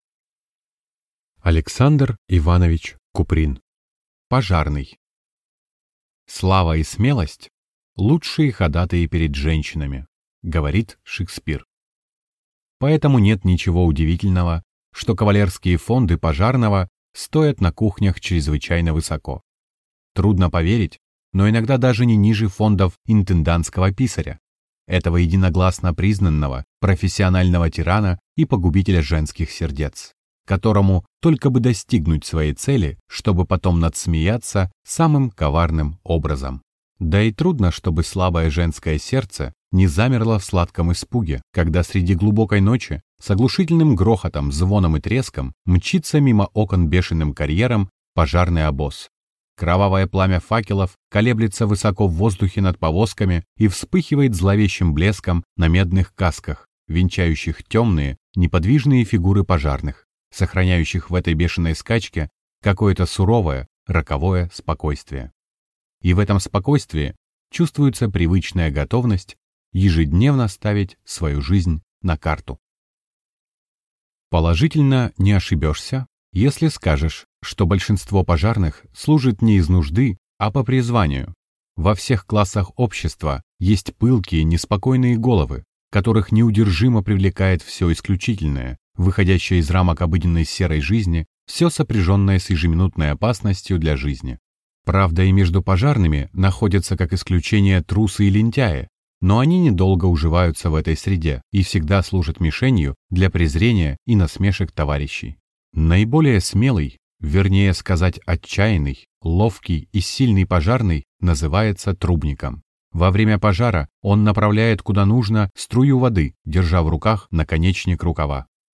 Аудиокнига Пожарный | Библиотека аудиокниг